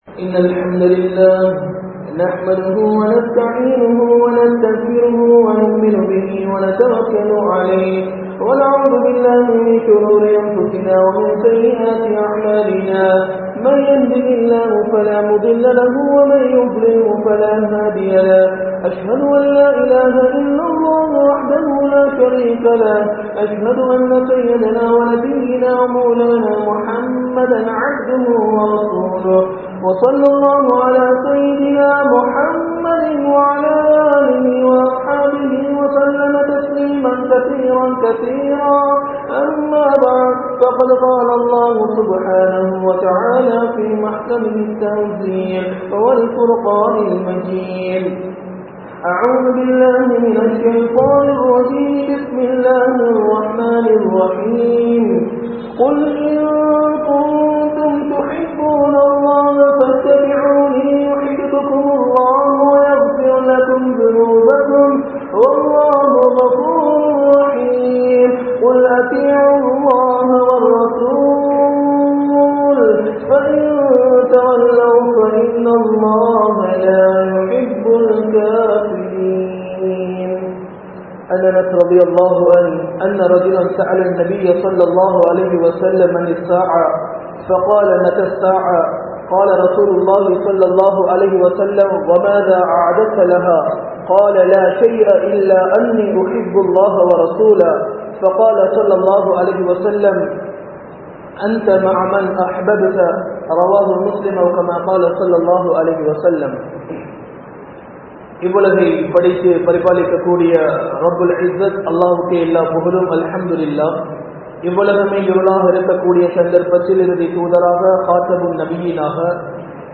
Marumaiel Suvanaththil Nabiudan Inainthirukka Enna Vali? (மறுமையில் சுவனத்தில் நபியுடன் இணைந்திருக்க என்ன வழி?) | Audio Bayans | All Ceylon Muslim Youth Community | Addalaichenai
Colombo 04, Majma Ul Khairah Jumua Masjith (Nimal Road)